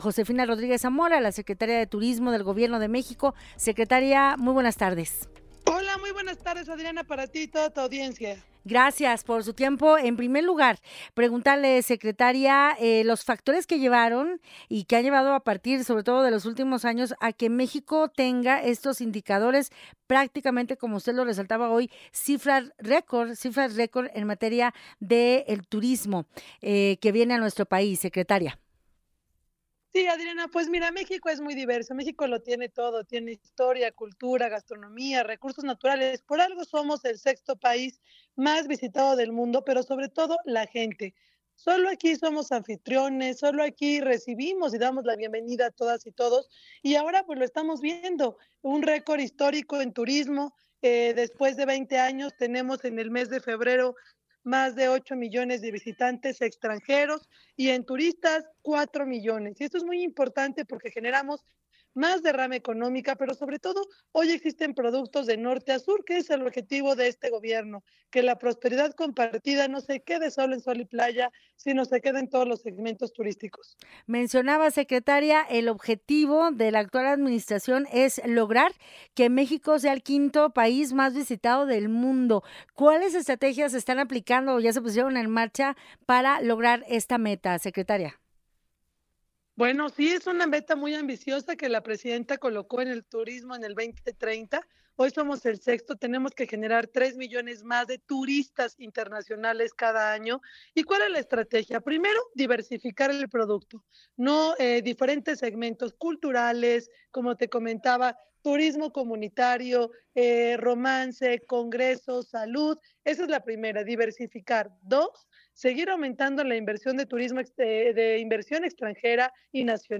Escucha nuestra conversación con Josefina Rodríguez Zamora